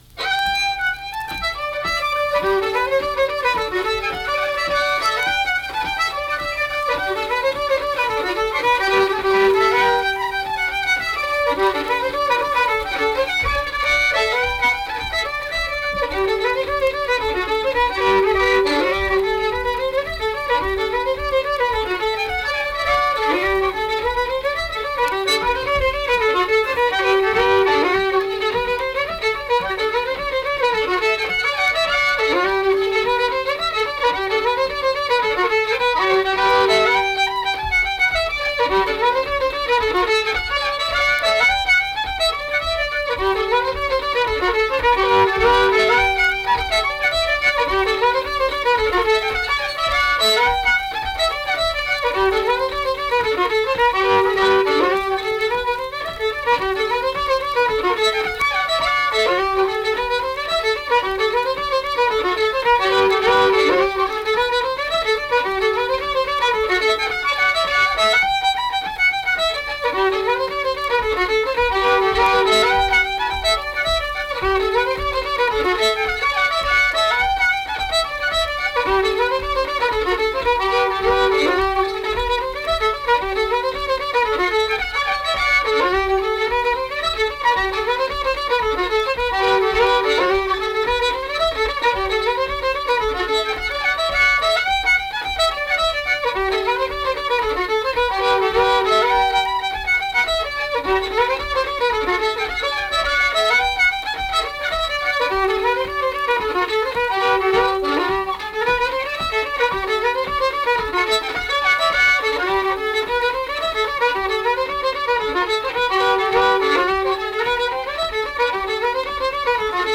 Buffalo Girls - West Virginia Folk Music | WVU Libraries
Accompanied guitar and unaccompanied fiddle music performance
Instrumental Music
Fiddle